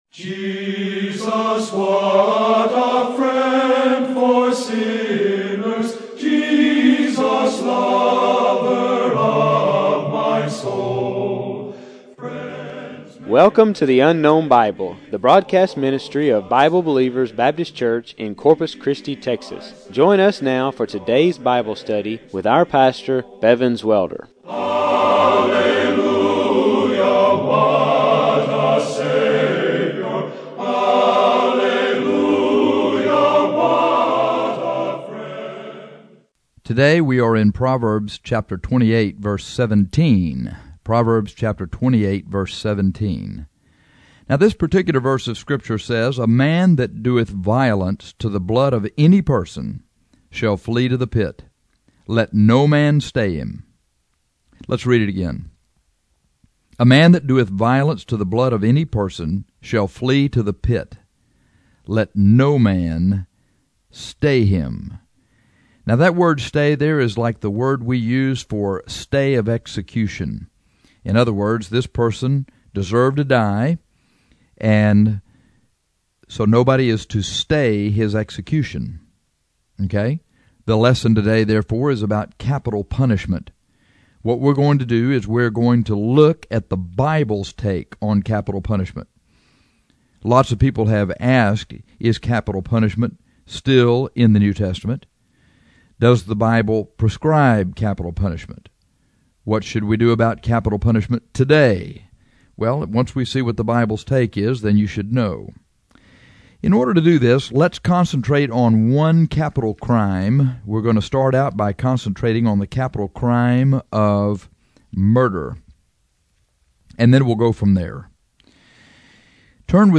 This lesson is about capital punishment.